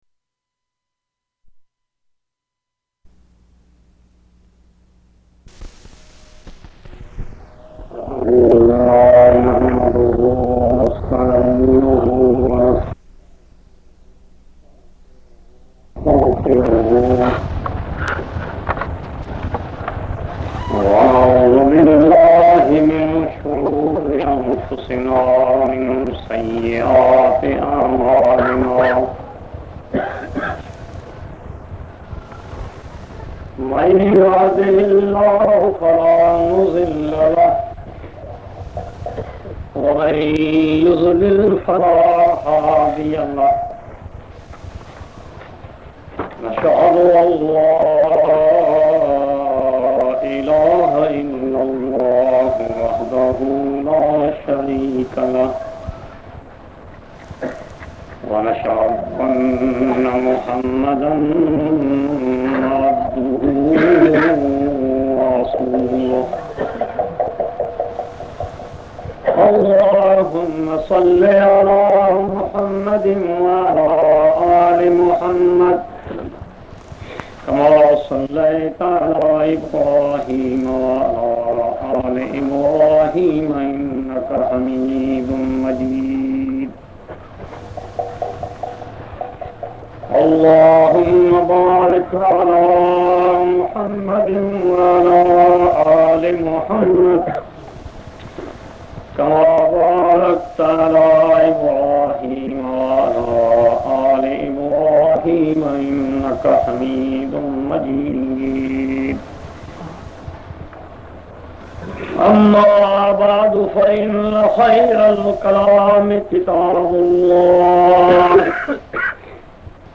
The-Way-of-Ahlul-Hadeeth-different-lecture.mp3